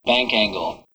bank-angle.wav